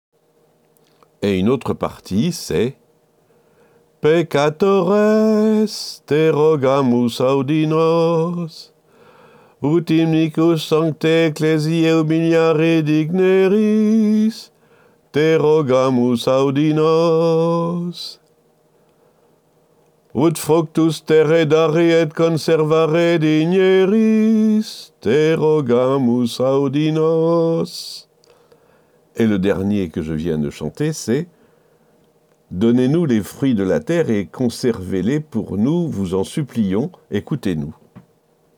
On y chantait les litanies, en tête, le curé et les choristes, suivis des fidèles.